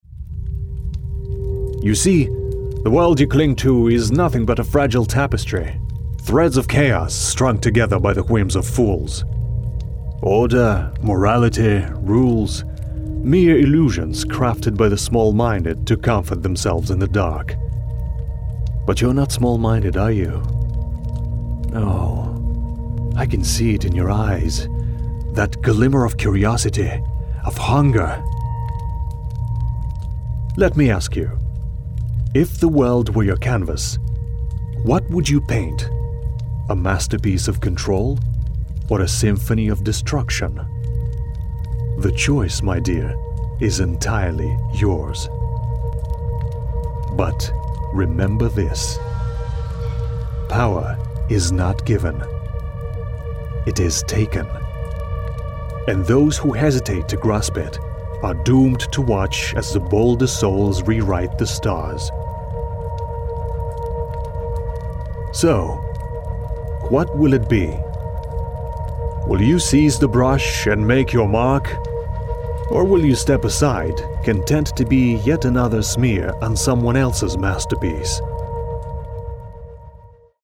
Male
Character / Cartoon
Mid-Atlantic / Transatlantic